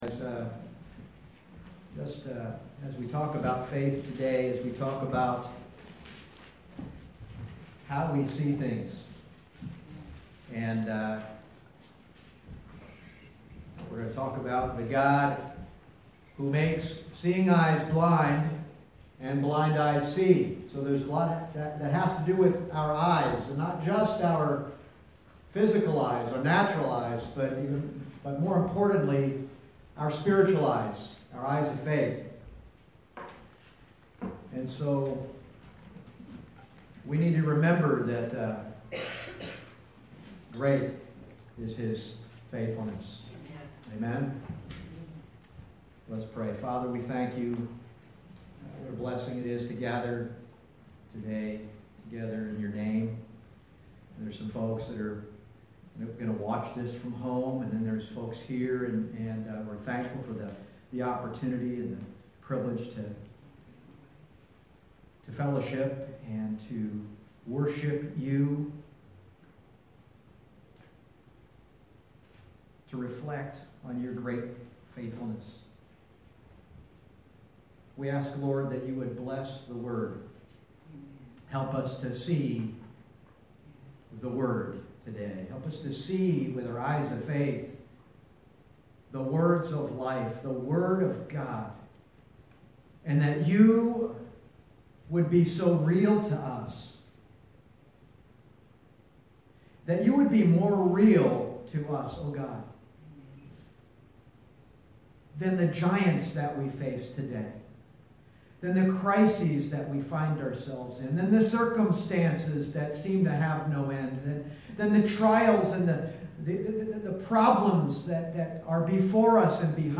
Passage: 2 Kings 6:8-23 Service Type: Sunday Morning